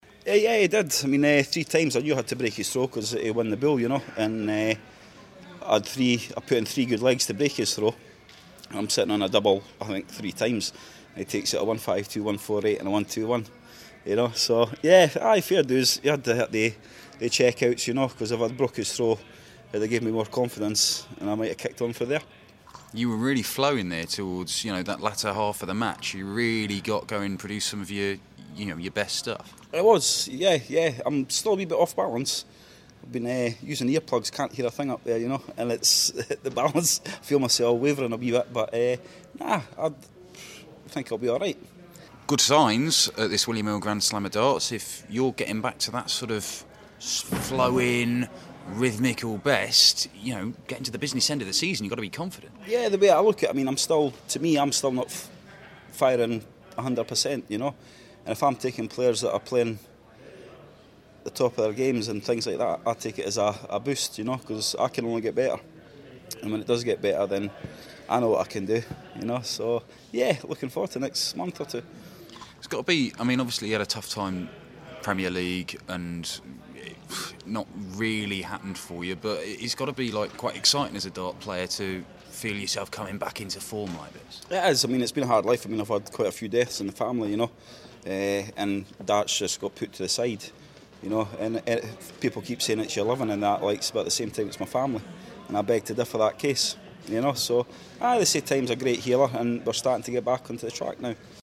William Hill GSOD - Anderson Interview (Last 16)